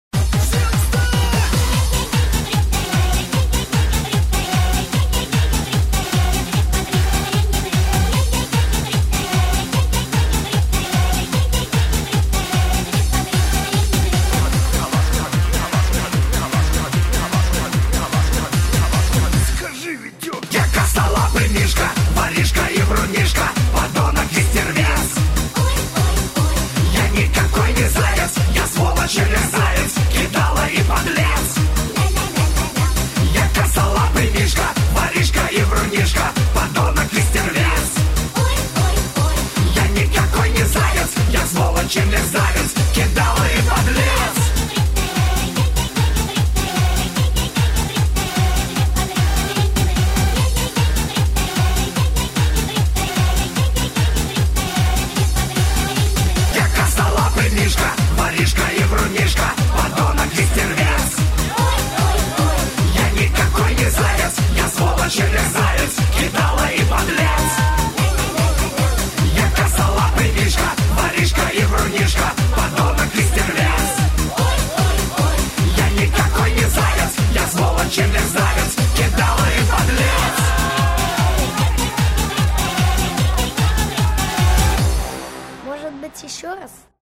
• Качество: 128, Stereo
пародия на песню